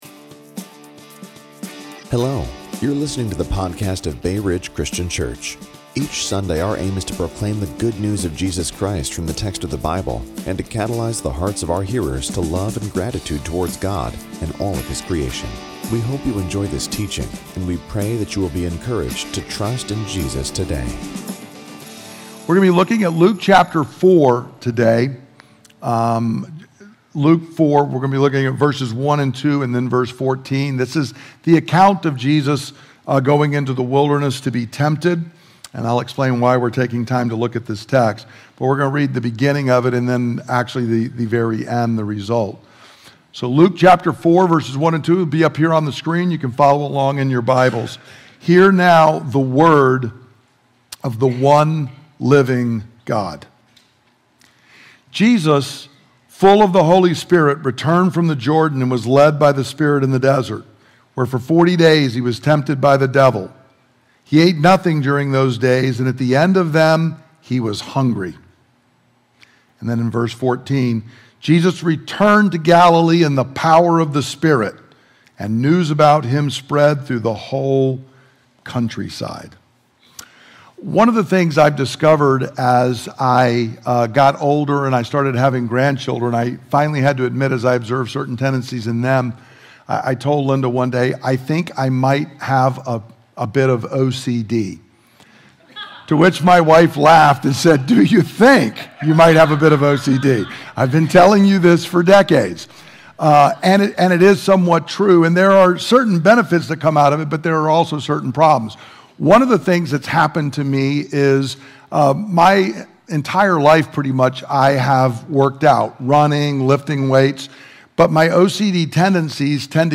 This podcast contains teachings from Bay Ridge Christian Church's Sunday service.